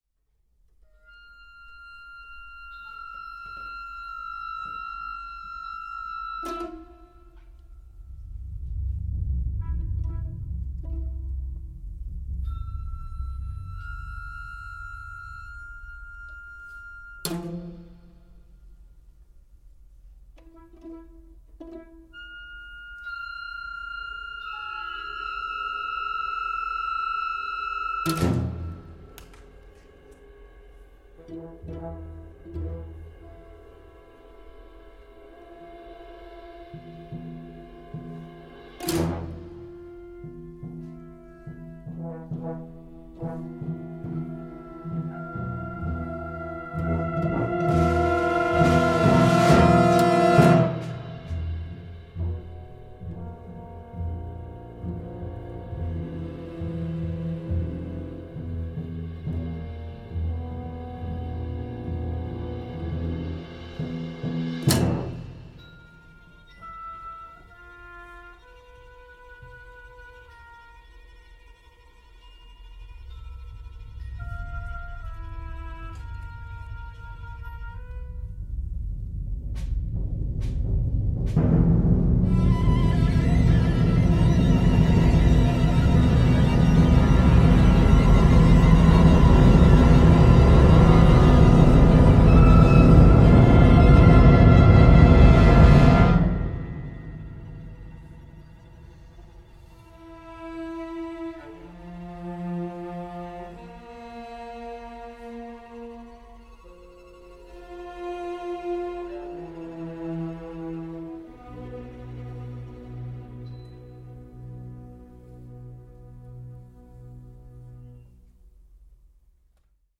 Large Ensemble Music (10+ musicians)
Piece for Orchestra (2024)